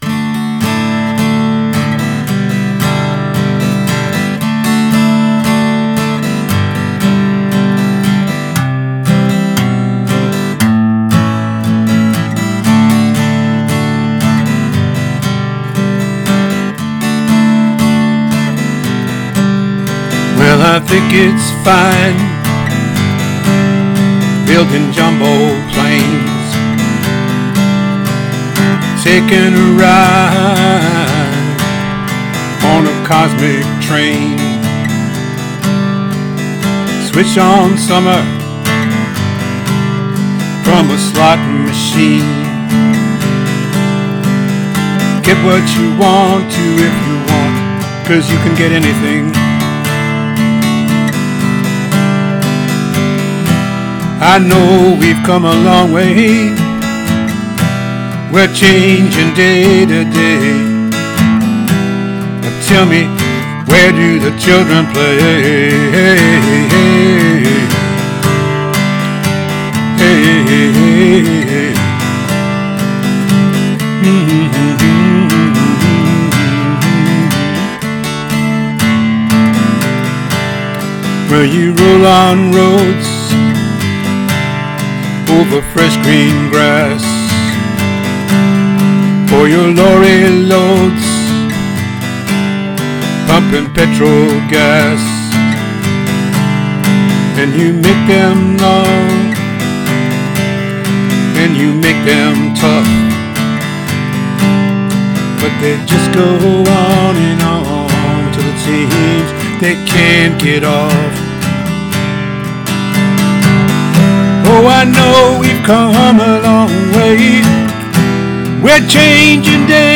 Here’s my cover.